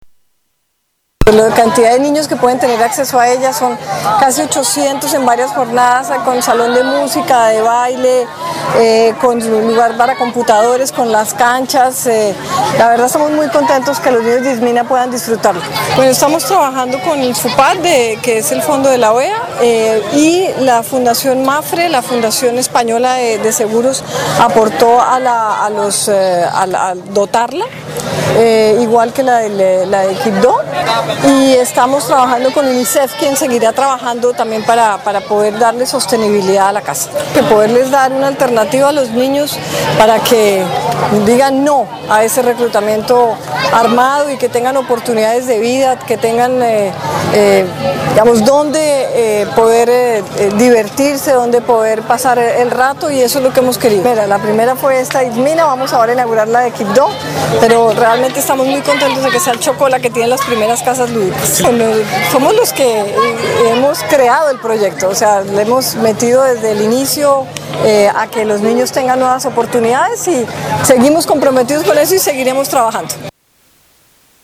Esta es la declaración de la Canciller María Ángela Holguín tras inaugurar las Casas Lúdicas en Chocó